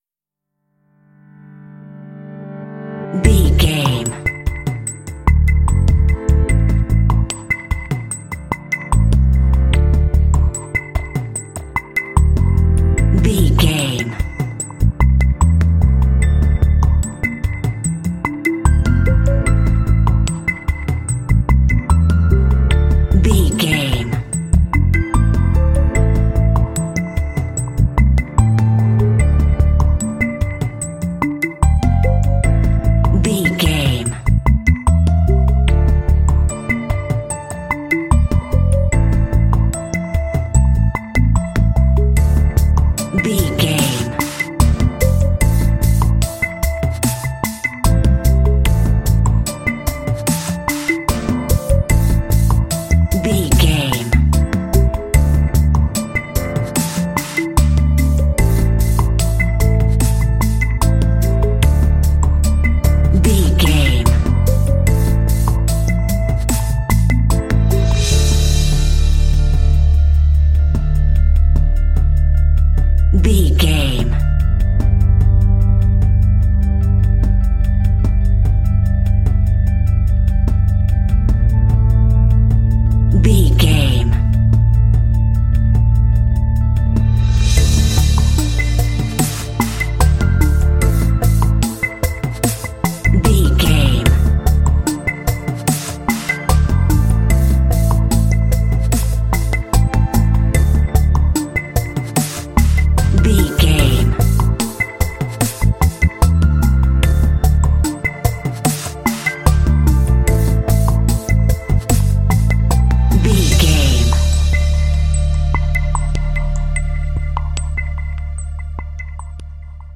Light and suspenseful, ideal for adventure games.
Aeolian/Minor
D
suspense
sad
dramatic
piano
bass guitar
strings
drums
contemporary underscore